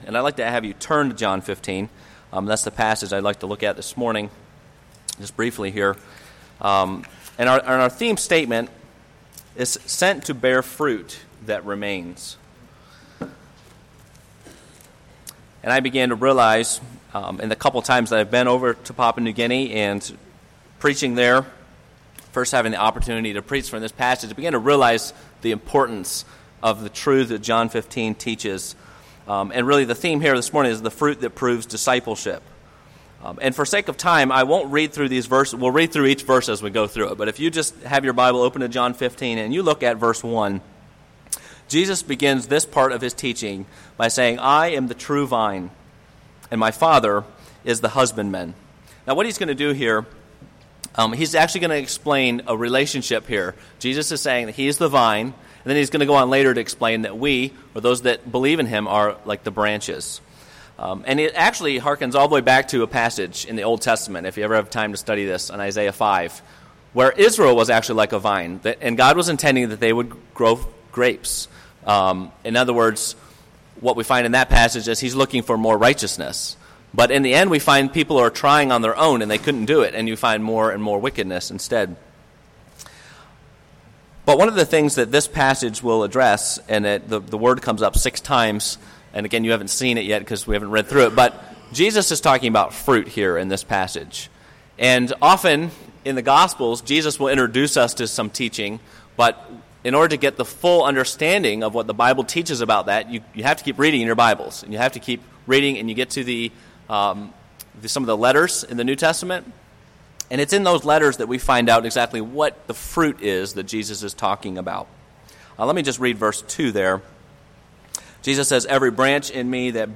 Westpointe Baptist Church Sermons